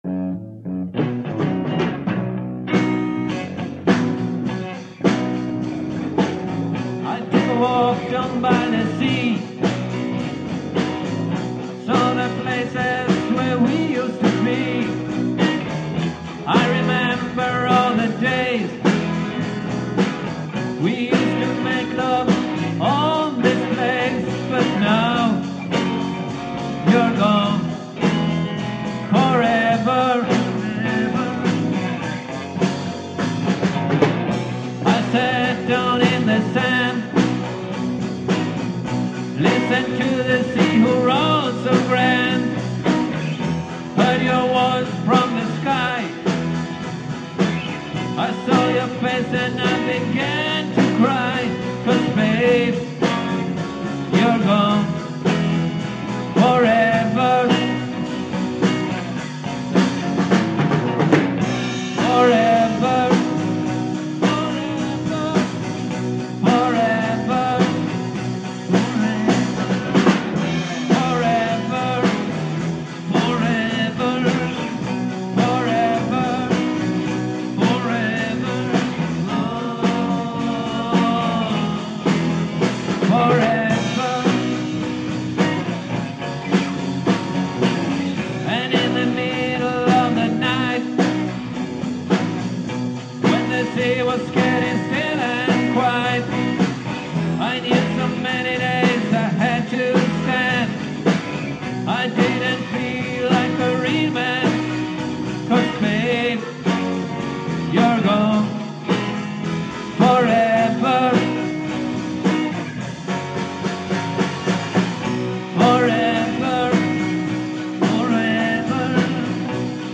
sångare
gitarr
trummor